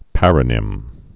(părə-nĭm)